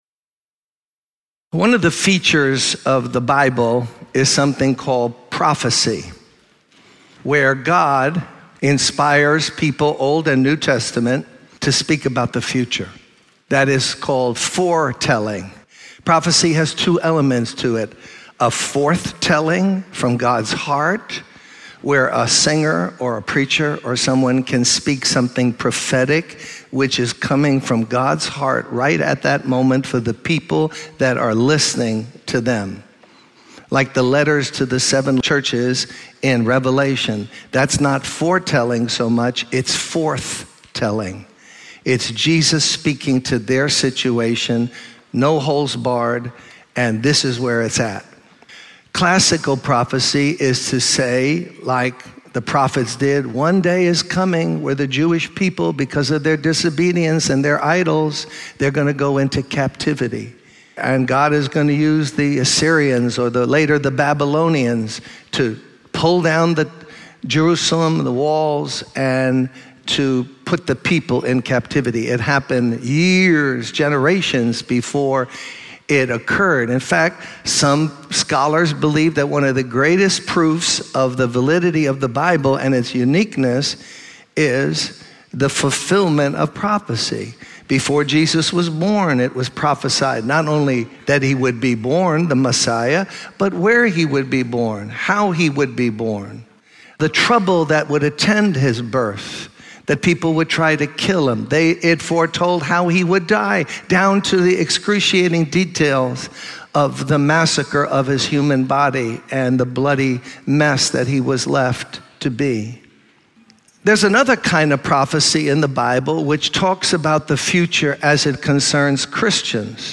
In this sermon, the speaker addresses the need to defend the faith that God has entrusted to his people. He warns against the influence of ungodly individuals who promote immoral living under the guise of God's grace.